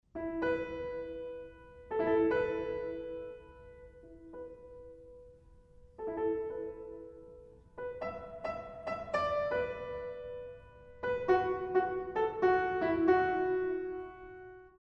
in E Minor